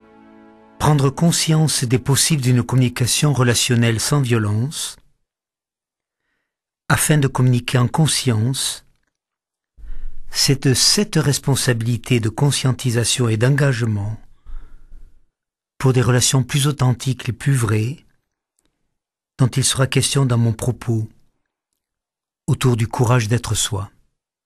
Extrait gratuit - Le courage d'être soi de Jacques Salomé
0% Extrait gratuit Le courage d'être soi de Jacques Salomé Éditeur : Coffragants Paru le : 2009 Lu par l'auteur L'ouvrage le plus personnel de Jacques Salomé nous interpelle sur l'art de communiquer en conscience.